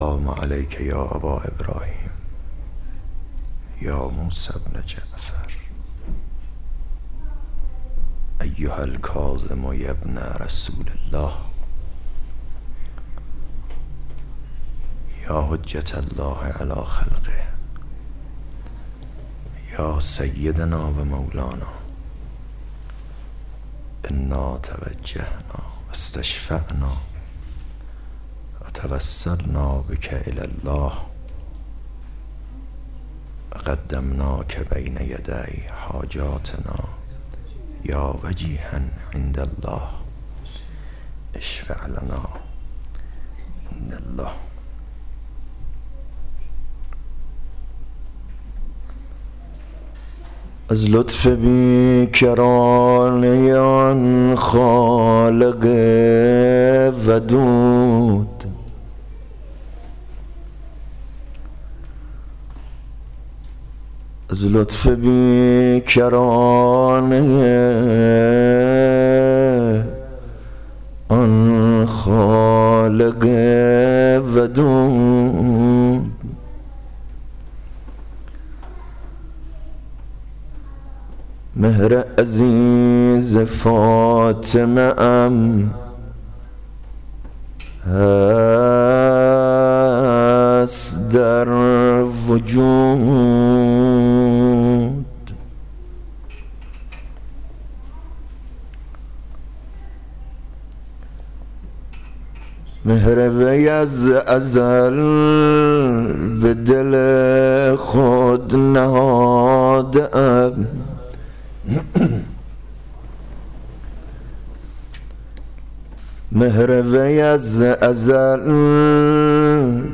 دسته بندی : مجالس روضه و مدح آل الله الاطهار علیهم صلوات الله الملک الجبار